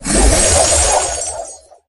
lou_throw_01.ogg